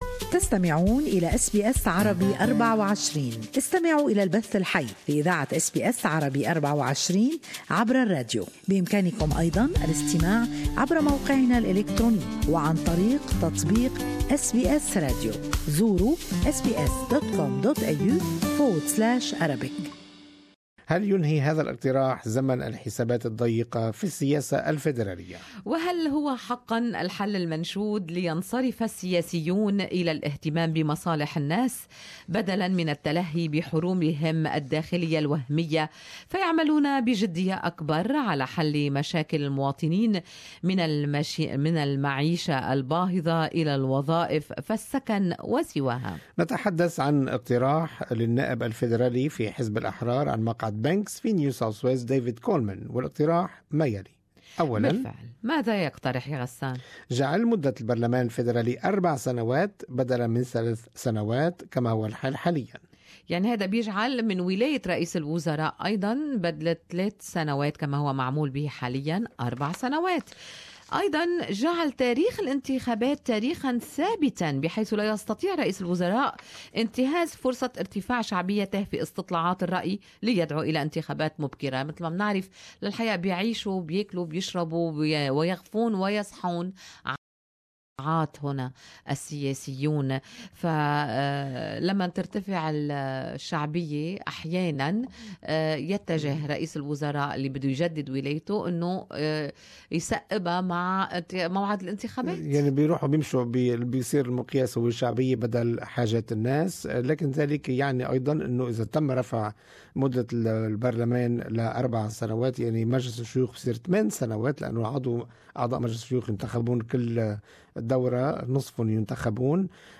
Good Morning Australia listeners share their opinions in the live talk back segment.